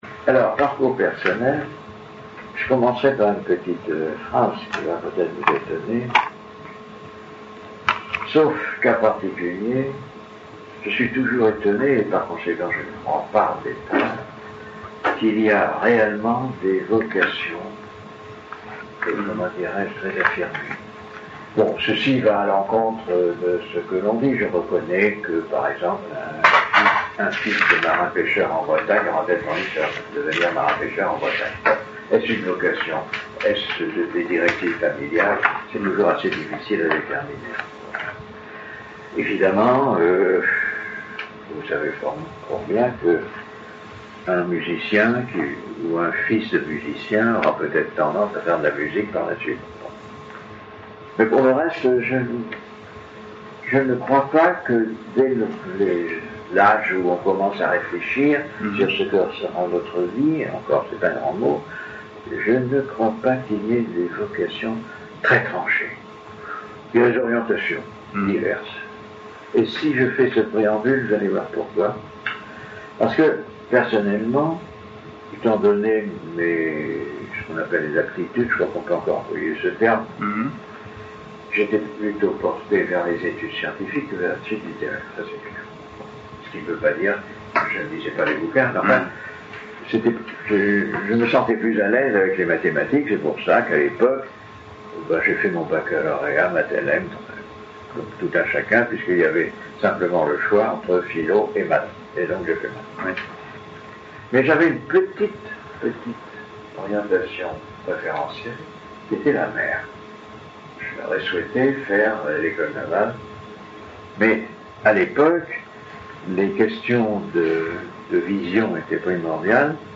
Ecoutez ici des extraits des entretiens thématiques initiaux